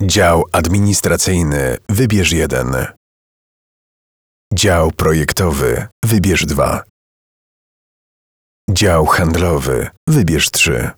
Głos o niskiej intonacji, z subtelnym ciepłem, które wprowadza słuchacza w spokojny, intymny nastrój. Jego niska tonacja nadaje każdemu słowu charakteru, a głębia brzmienia sprawia, że jest przyjemny w odbiorze.
Centralka telefoniczna: realizacja dla W&H Hetmaniok